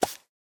brush_armadillo1.ogg